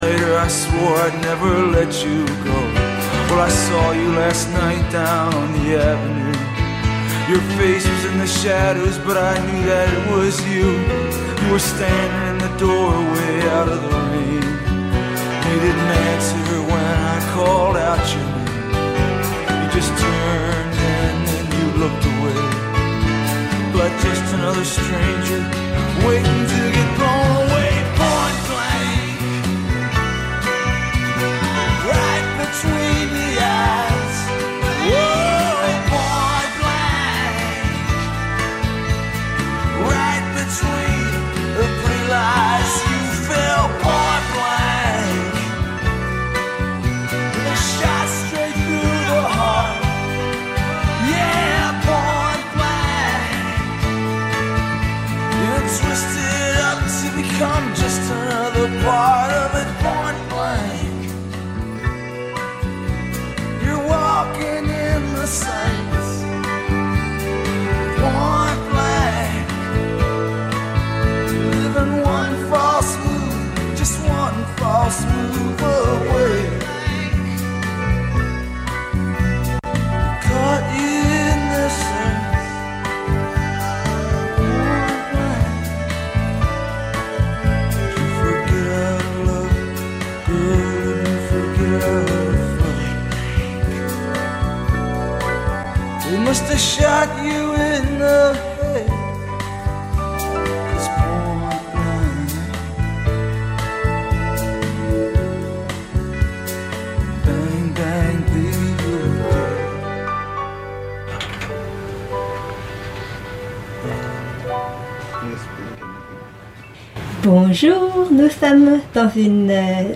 Es ist eine Sendung von Gefl�chteten und MigrantInnen in Halle und Umgebung.